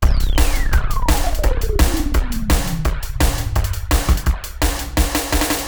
SWEEP BEAT 1.wav